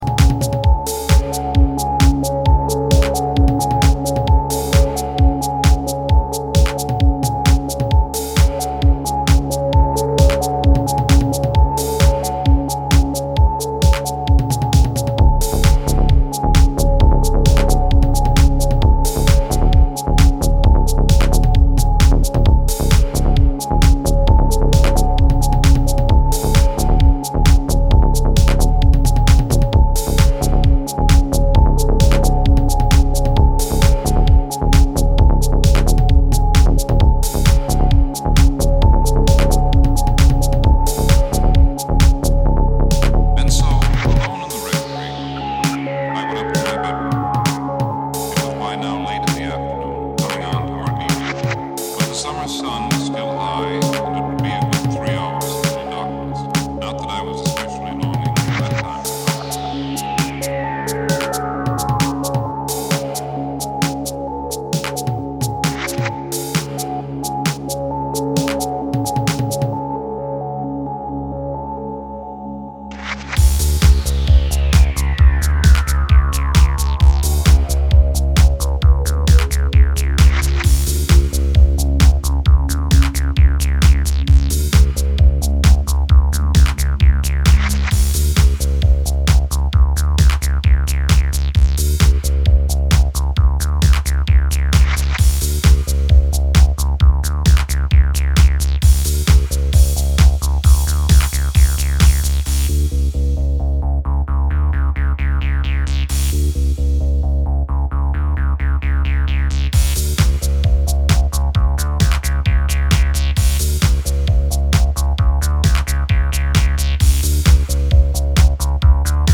a dark, hypnotic, yet elegant release